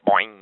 boing.mp3